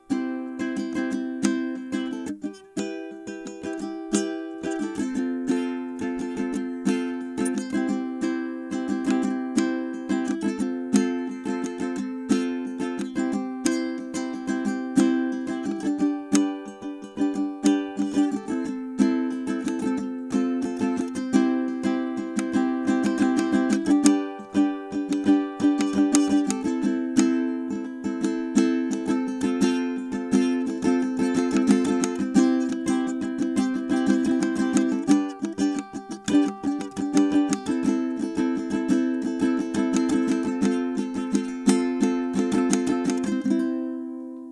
Tenor Ukulele #10 – Bloo – SOLD